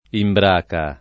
imbraca [ imbr # ka ]